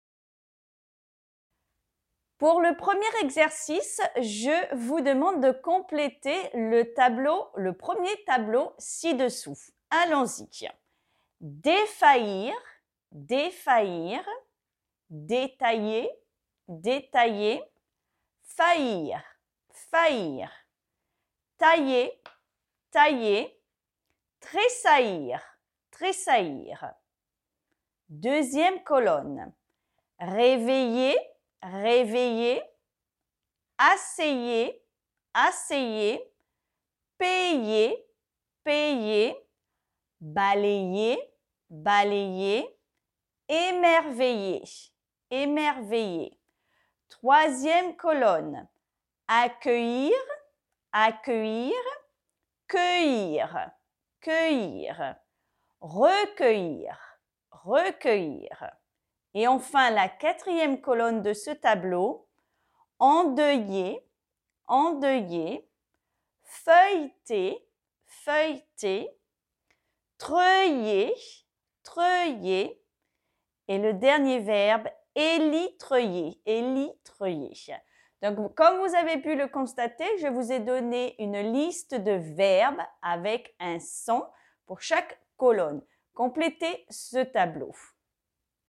[aj]  [ɛj] [œj]